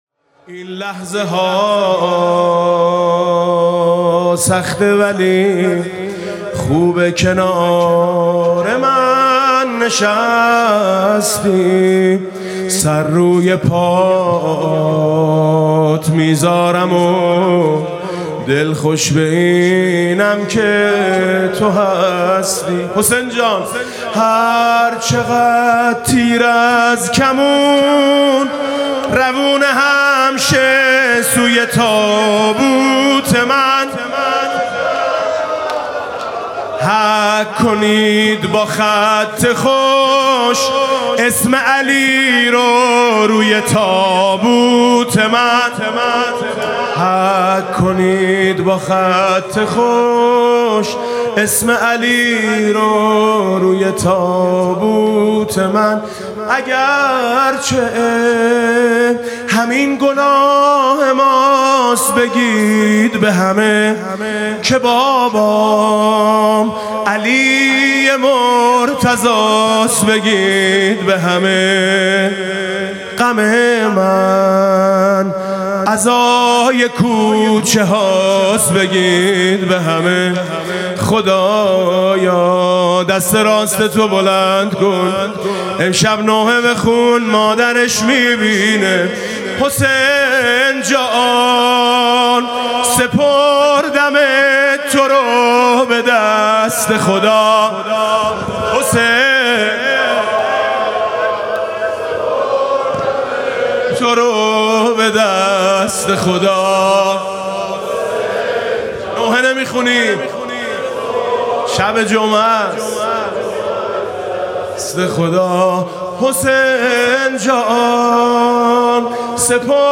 امامزاده قاضی الصابر (علیه‌السّلام)